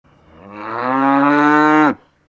cow-mooing.wav